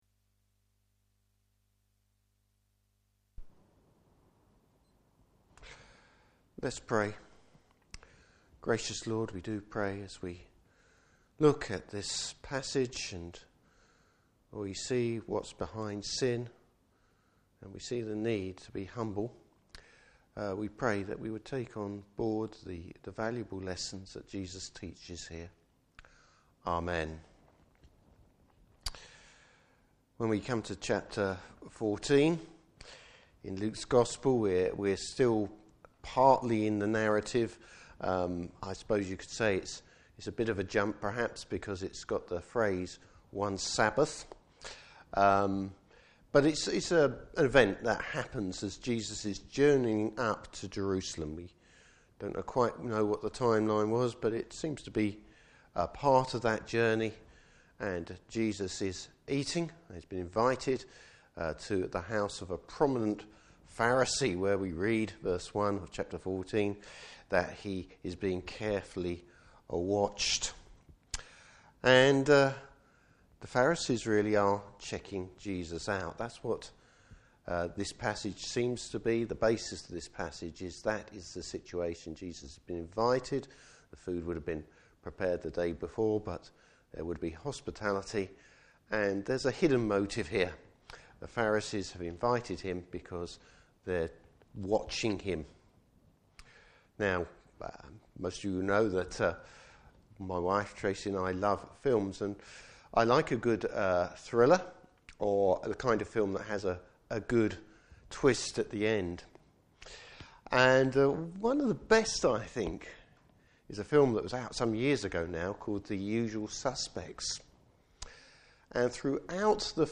Service Type: Morning Service Bible Text: Luke 14:1-14.